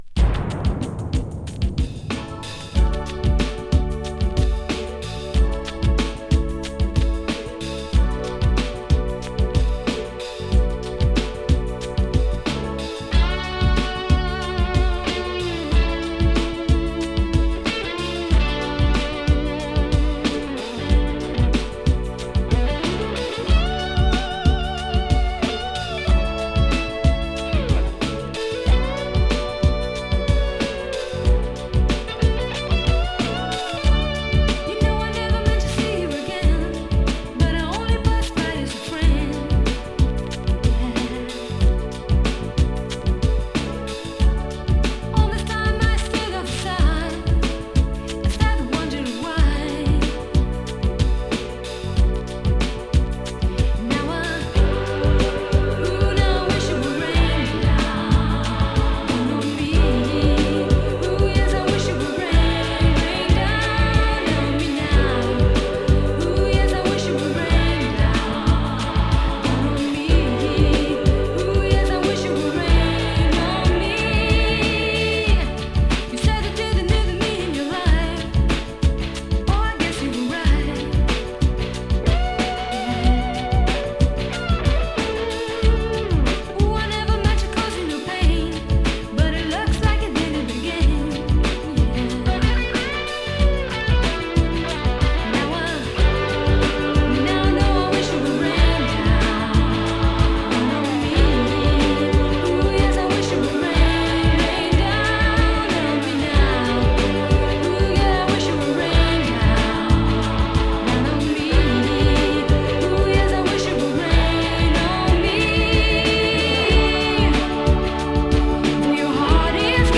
＊音の薄い部分で軽いチリパチ・ノイズ。
♪Club Mix (5.38)♪